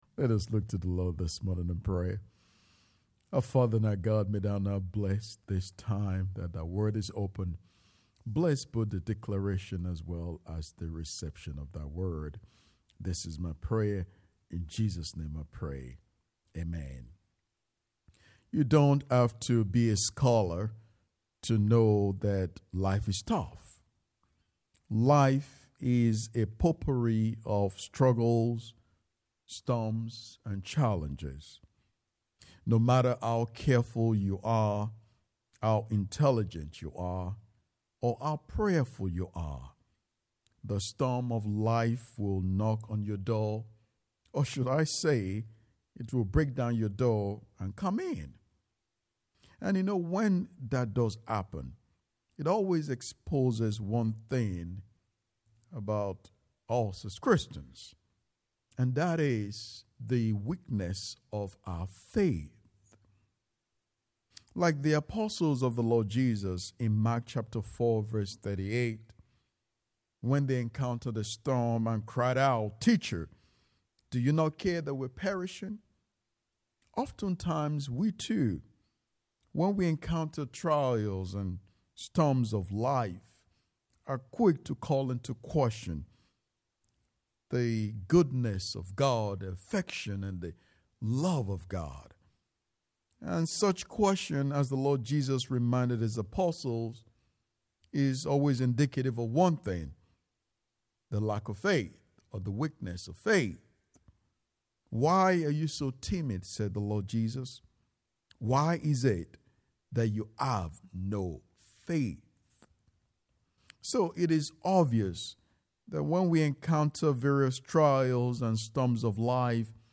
10:30 AM Service
10:30 AM Service Faith It Until You Make It Click to listen to the sermon. https